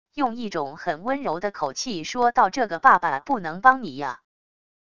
用一种很温柔的口气说道这个爸爸不能帮你呀wav音频